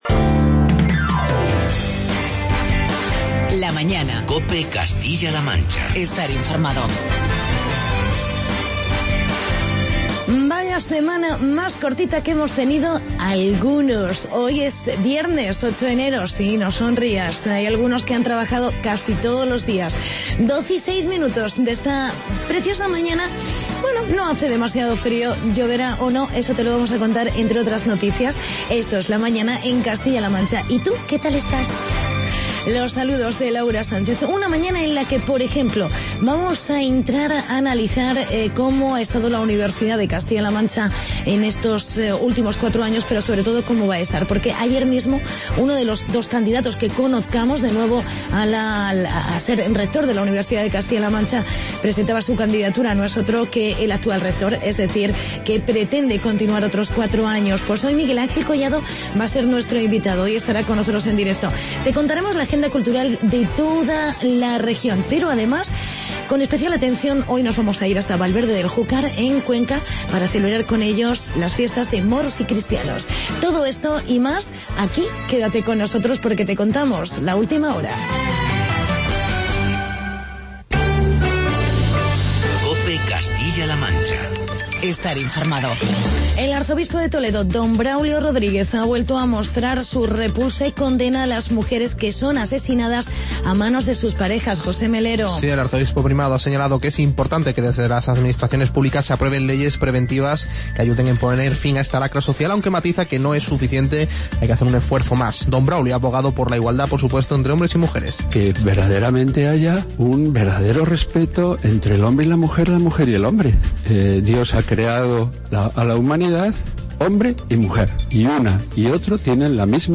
Entrevista con Miguel Angel Collado, candidato a la reelección como Rector de la UCLM y Agenda Regional.